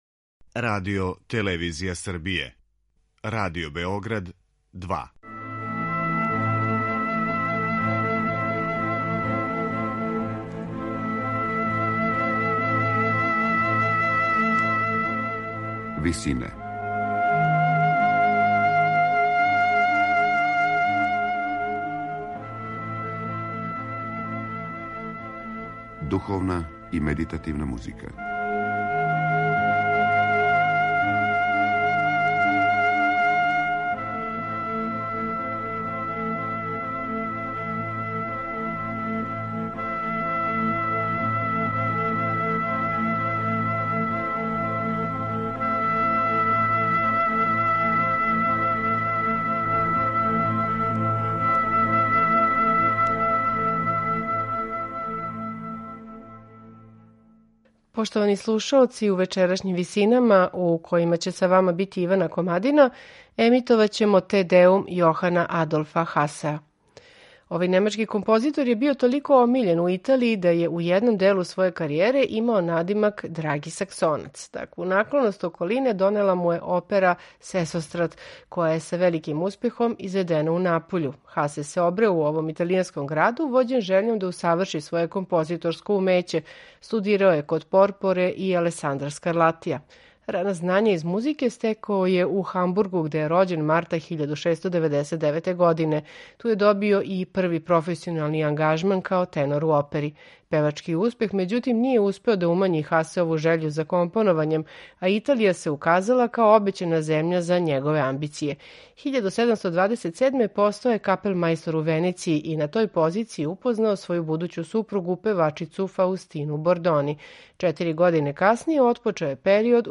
Te Deum је композиција веома сведеног стила, заснована на једном мотиву, замишљеном тако да остави што величанственији утисак у новоизграђеној цркви.
сопран
алт
тенор